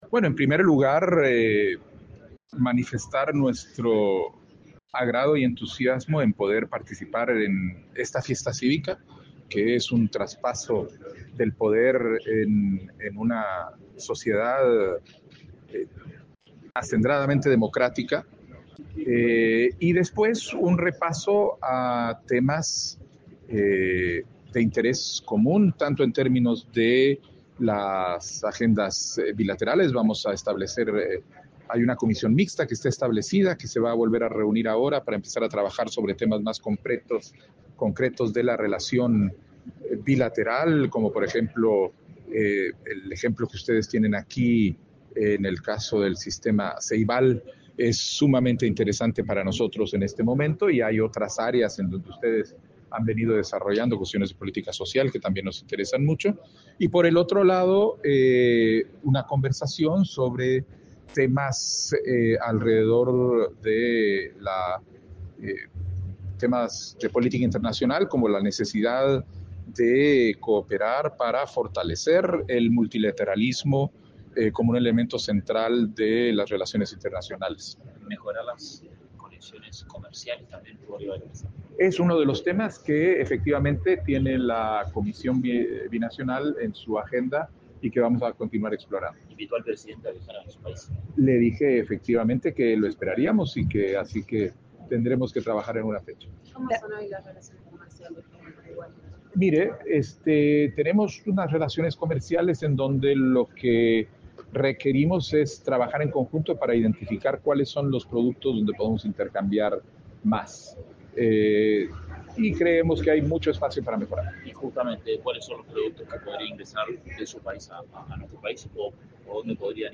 Declaraciones a la prensa del presidente de Guatemala, Bernardo Arévalo
Declaraciones a la prensa del presidente de Guatemala, Bernardo Arévalo 02/03/2025 Compartir Facebook X Copiar enlace WhatsApp LinkedIn El presidente de la República, Yamandú Orsi, y el canciller, Mario Lubetkin, se reunieron con el mandatario de Guatemala, Bernardo Arévalo, este 2 de marzo. Tras el encuentro, Arévalo realizó declaraciones a la prensa.